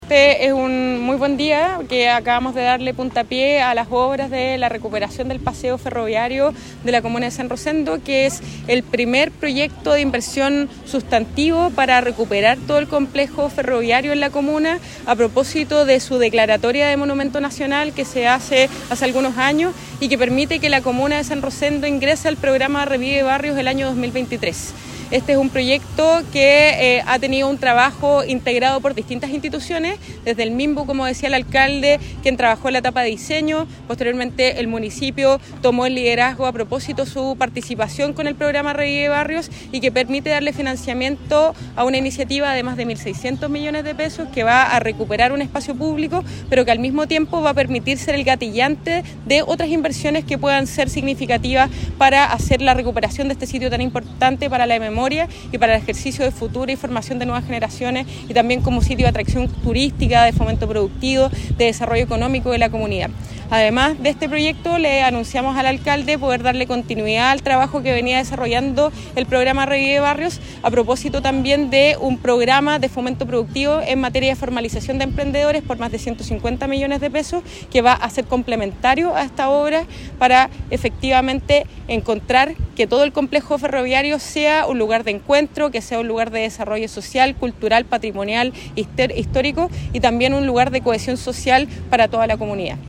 El evento contó con la presencia de la subsecretaria de Desarrollo Regional y Administrativo (Subdere), Francisca Perales, quien reafirmó la importancia del trabajo interinstitucional en la preservación y puesta en valor del patrimonio cultural.